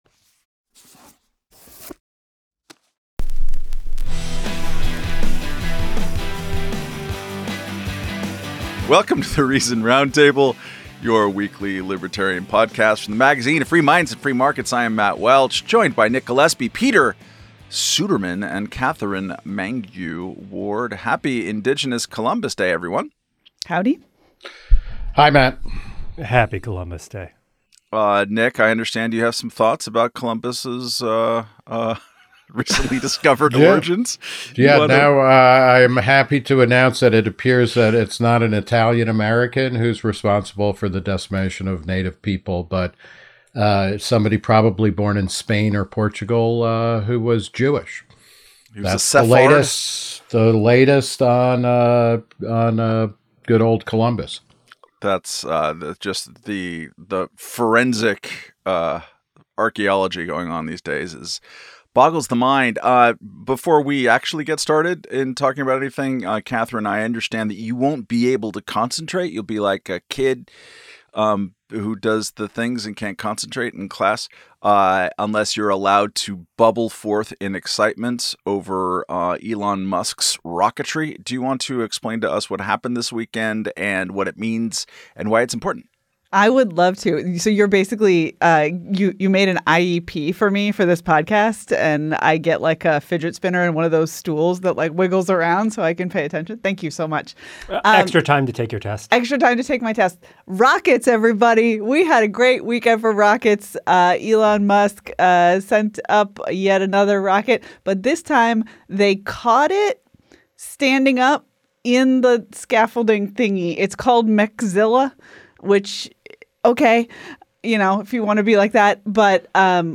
In this week's Reason Roundtable, we parse the presidential candidates' latest batch of confounding ideas on tax policy.